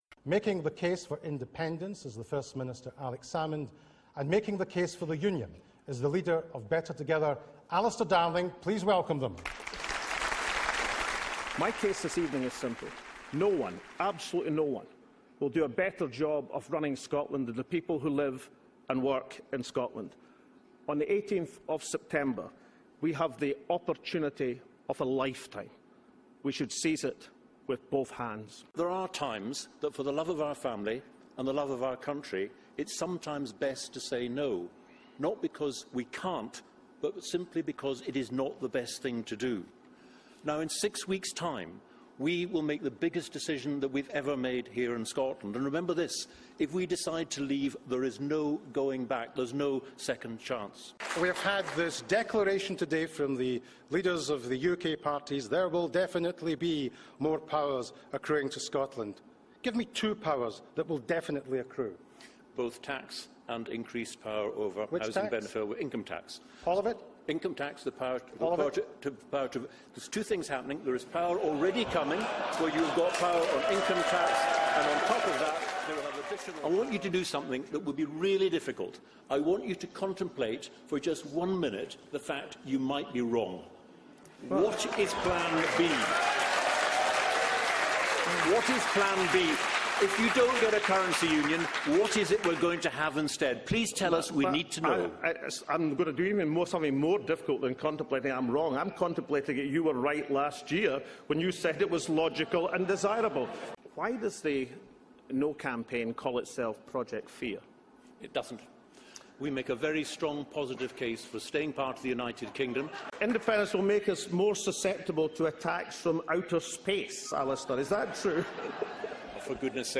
Referendum TV Debate Highlights
The best bits of last night's debate between Alex Salmond and Alistair Darling